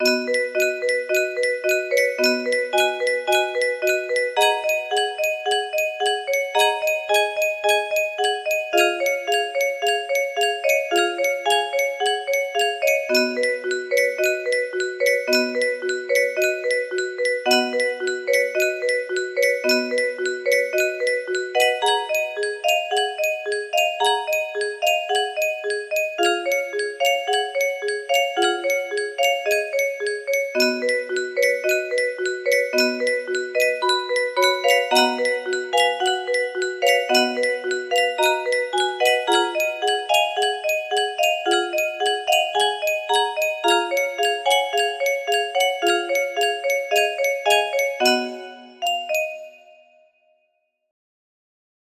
bare2 music box melody